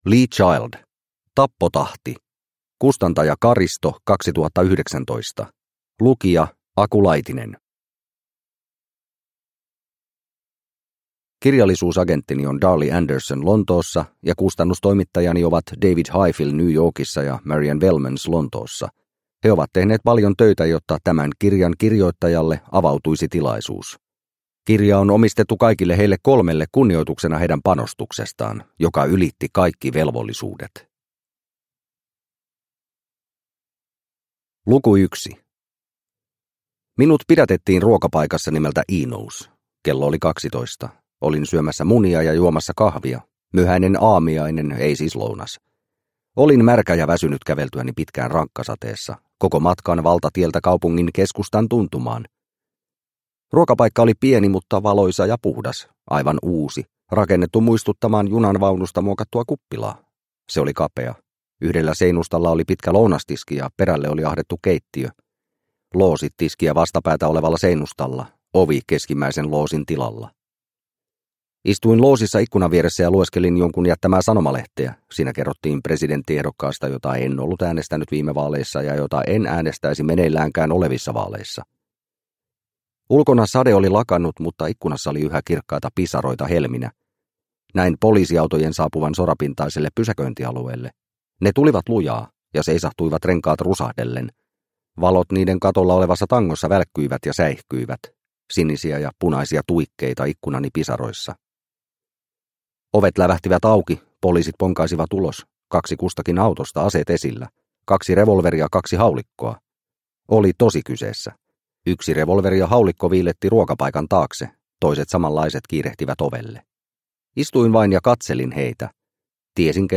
Tappotahti – Ljudbok – Laddas ner